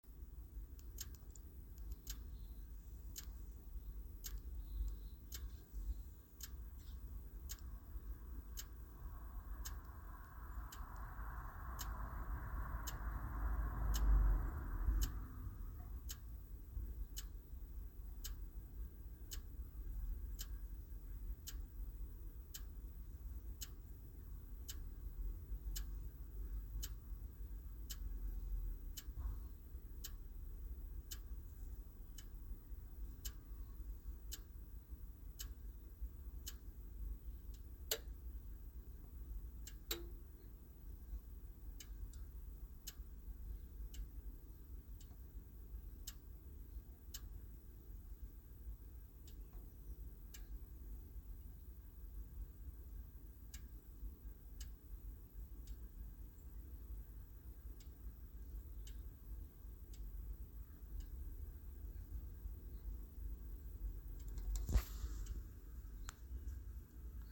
Il y a un clic sur l'UE.
Les clics se font lors de l'arrêt de l'UE avec le voyant Power vert qui clignote toutes les secondes.
On dirait un relais qui colle puis décolle 1/10è de seconde plus tard.